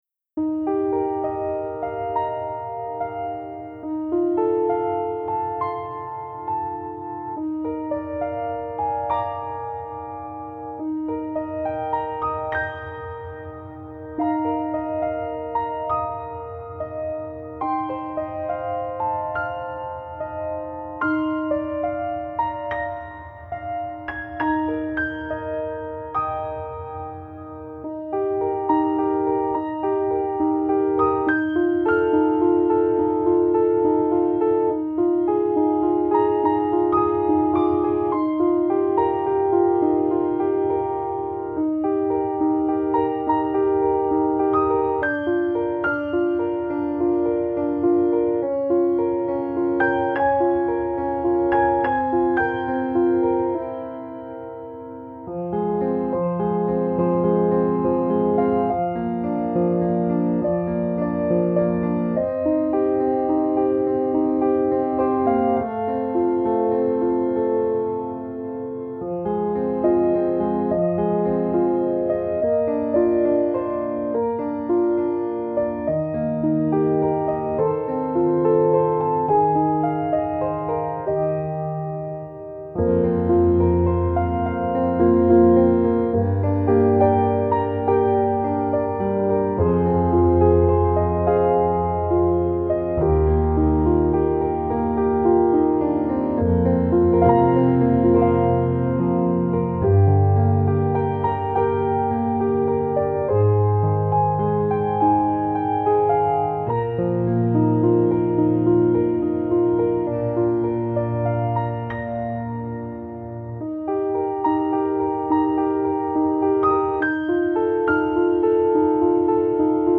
Laughter and Tears Solo Piano